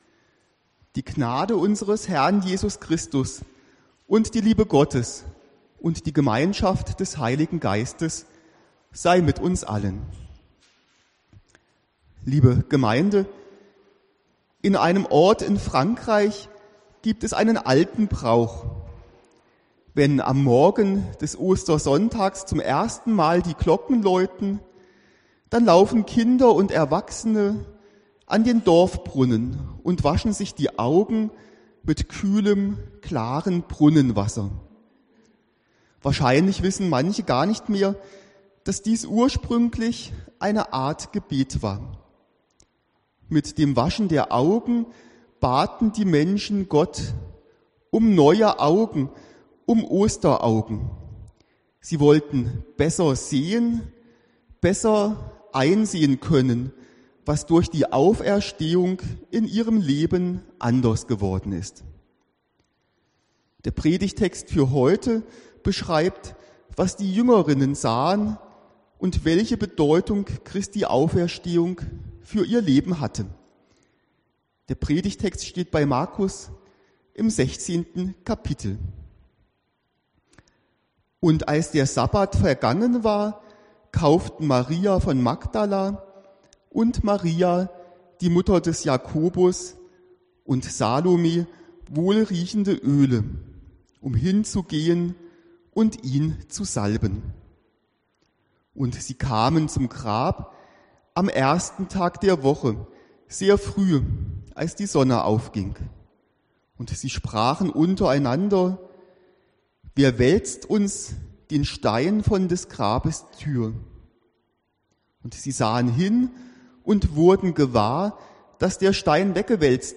(Ostern) Predigt